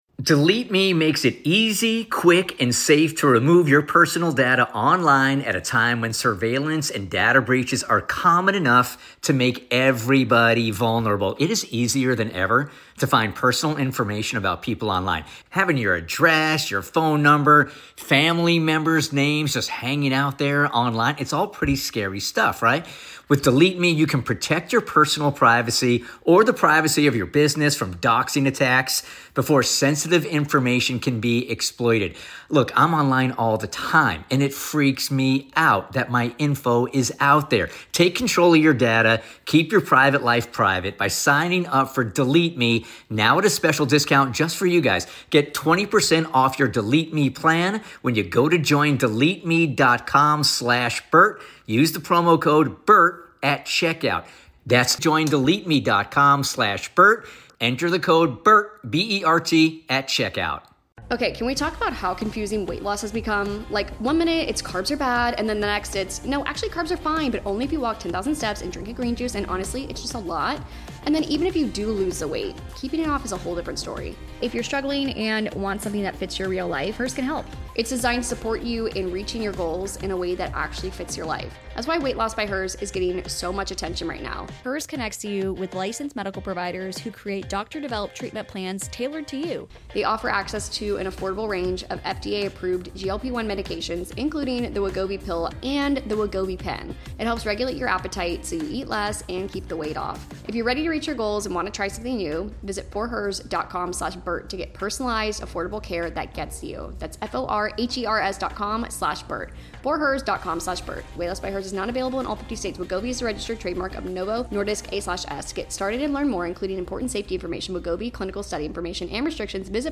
is in studio to explain how websites and apps are manipulating users...and it's worse than we thought!